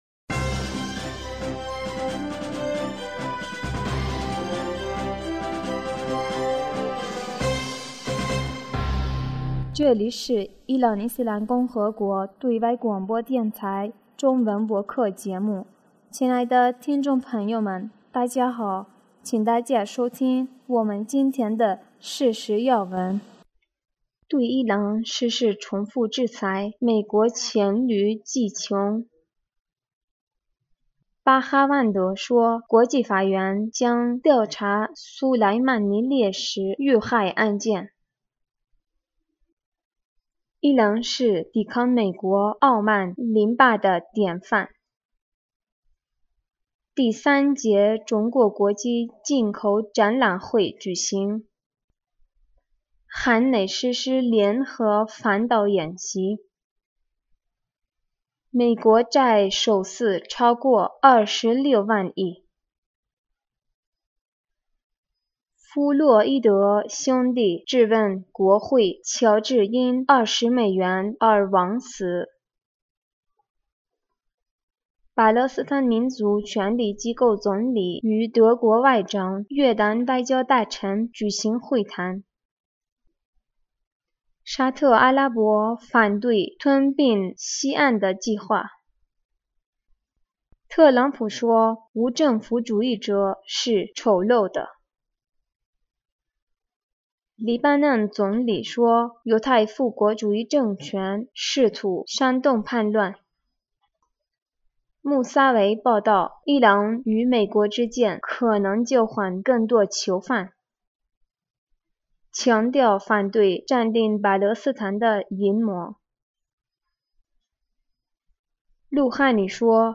2020年6月11日 新闻